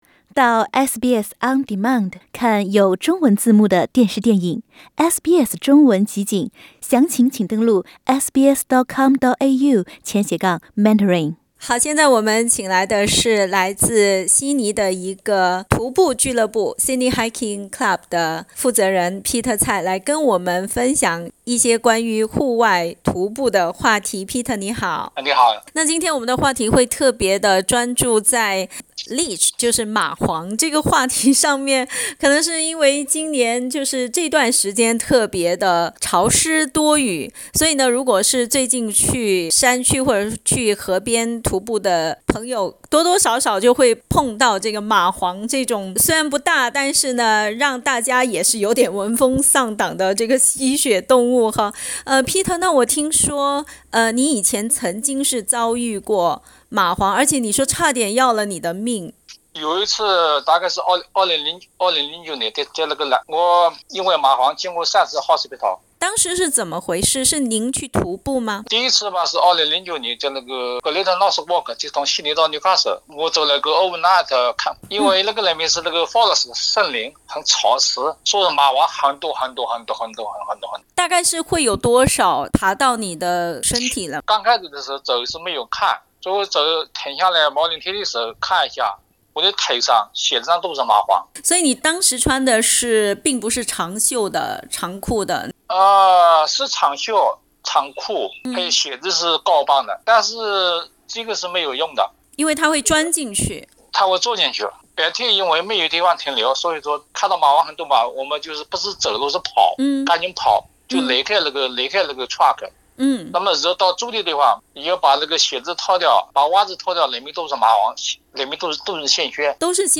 （請聽寀訪） 澳大利亞人必鬚與他人保持至少 1.5 米的社交距離，請查看您所在州或領地的最新社交限制措施。